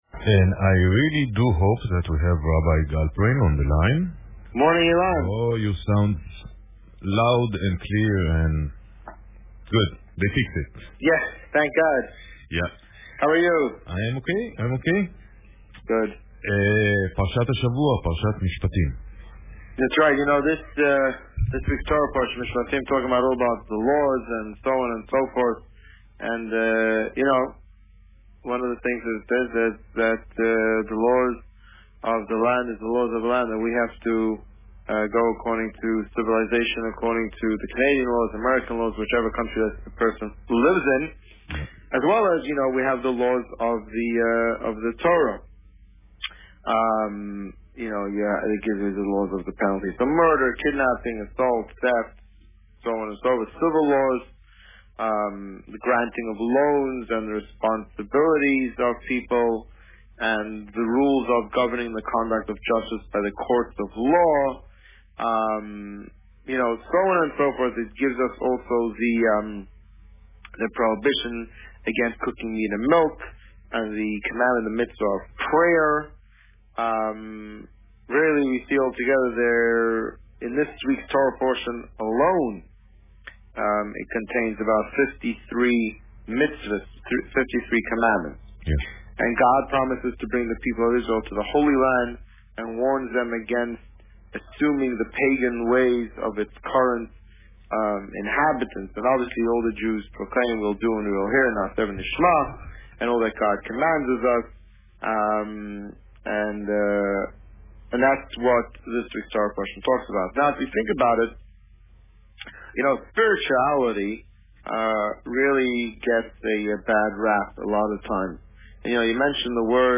This week, the Rabbi spoke about Parsha Mishpatim and plans for the upcoming Purim party.  Listen to the interview